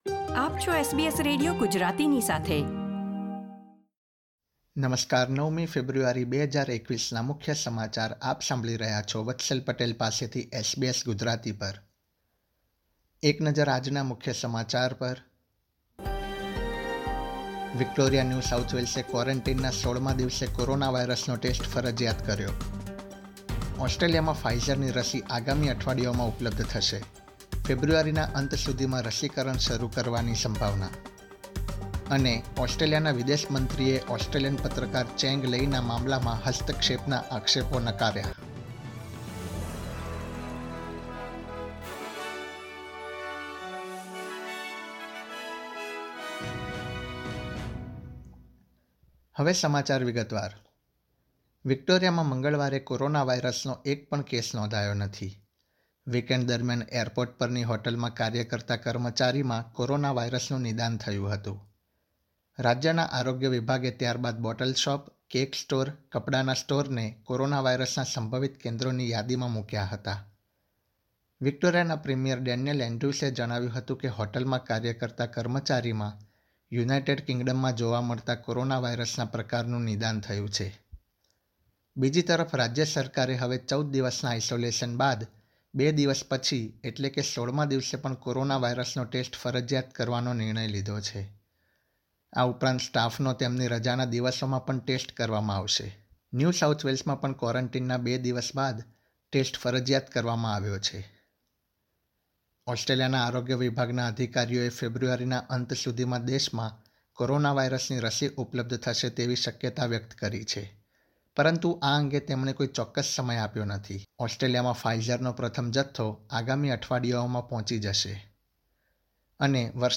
SBS Gujarati News Bulletin 9 February 2021
gujarati_0902_newsbulletin.mp3